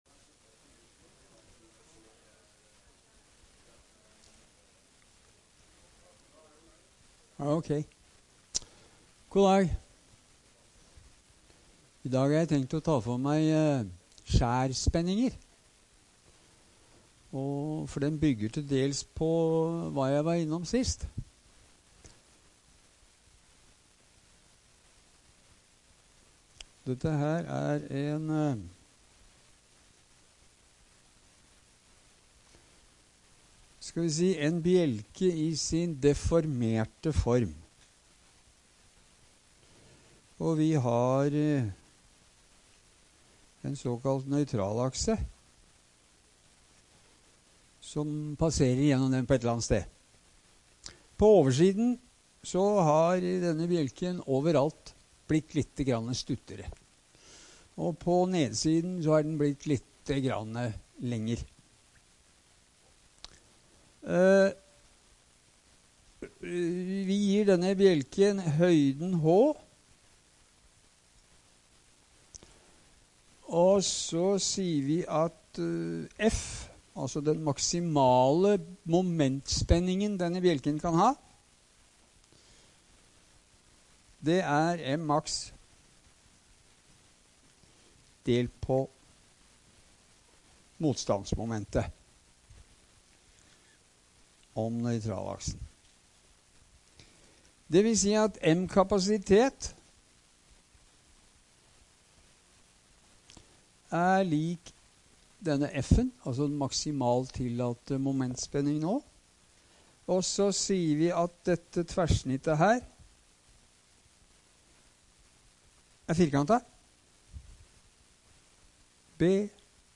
Mekanikk - NTNU Forelesninger på nett